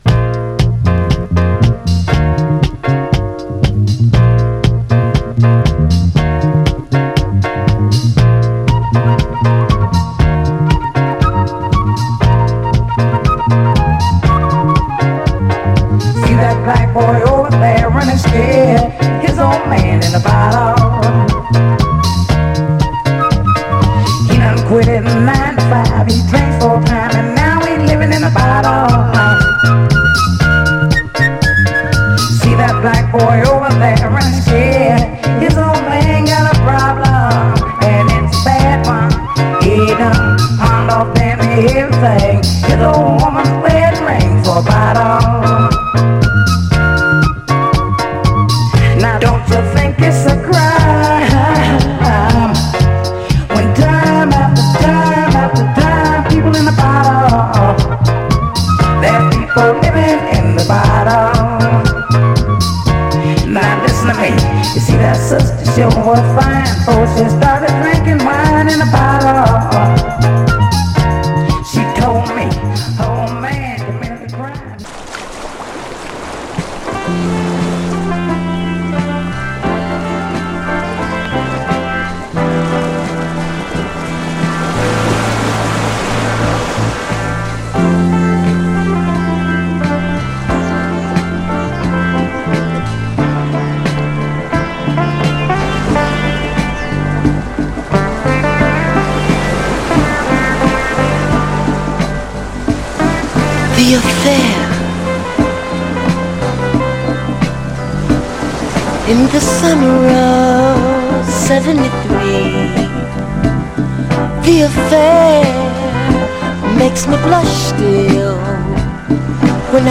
夏の終わりの哀愁を波音入り極上メロウ・ソウルで歌った
ただしこのタイトル特有の軽いプレスノイズあり。
※試聴音源は実際にお送りする商品から録音したものです※